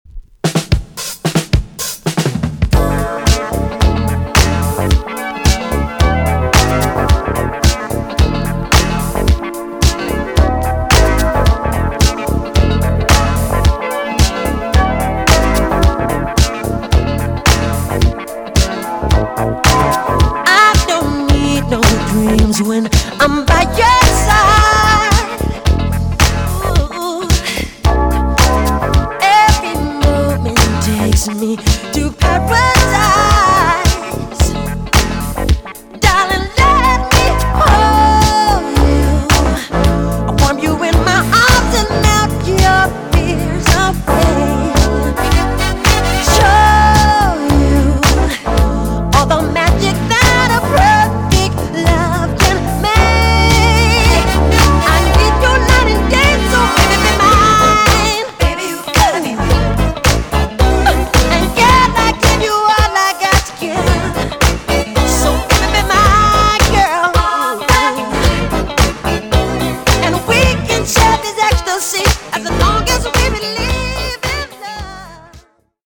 EX 音はキレイです。